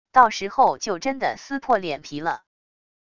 到时候就真的撕破脸皮了wav音频生成系统WAV Audio Player